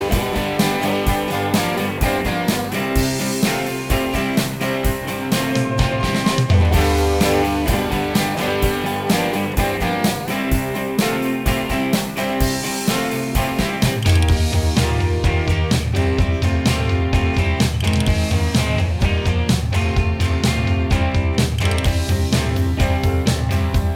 Minus Bass Guitar Rock 3:53 Buy £1.50